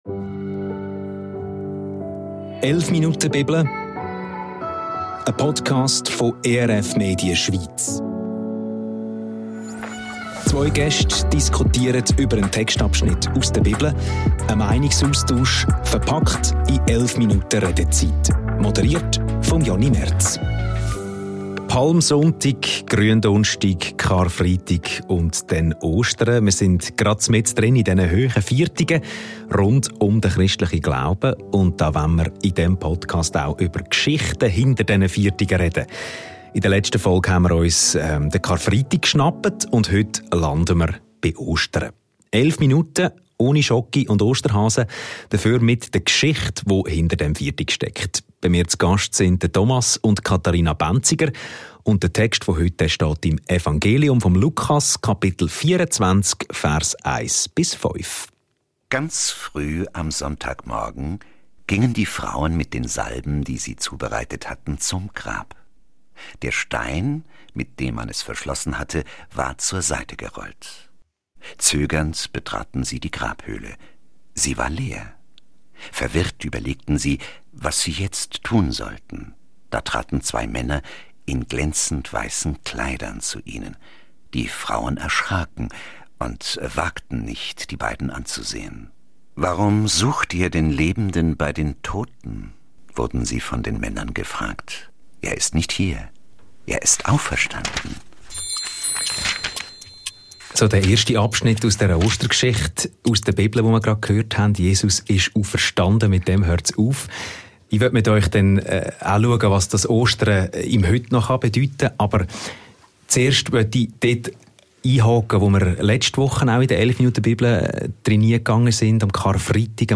So endet der Bibeltext, über den wir 11 Minuten diskutieren. Er endet mit einem «Häää? Was?», einem Moment der Ratlosigkeit der Frauen, die am Ostermorgen vor dem leeren Grab stehen.